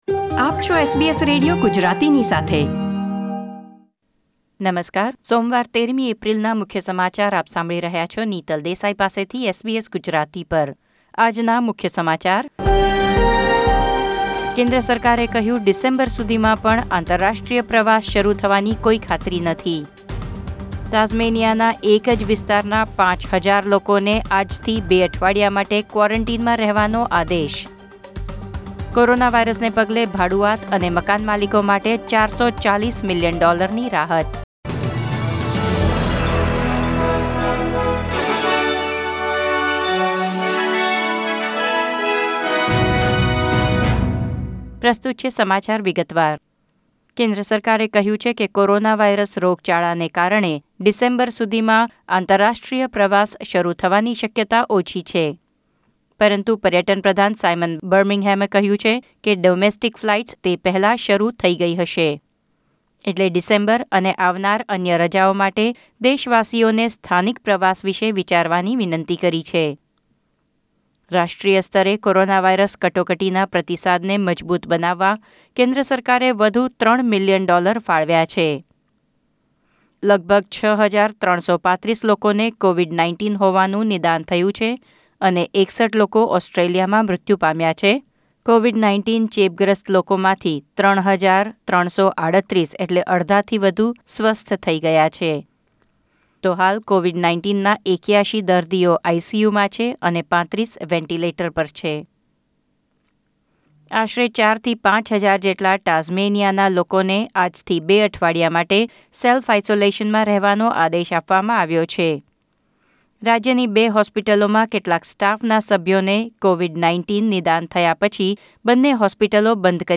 ૧૩ એપ્રિલ ૨૦૨૦ ના મુખ્ય સમાચાર